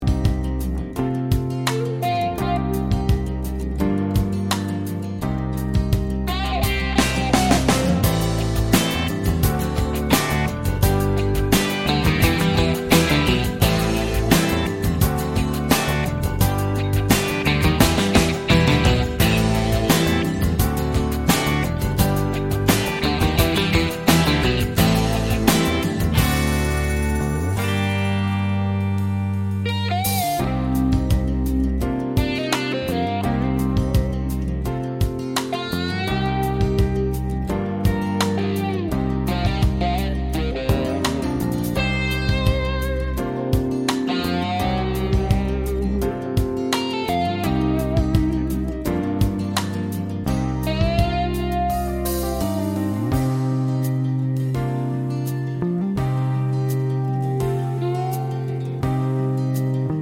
no Backing Vocals Country (Female) 4:10 Buy £1.50